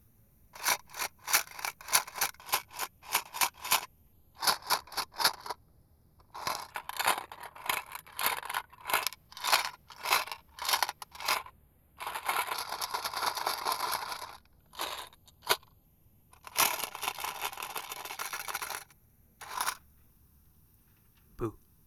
rattle_h.wav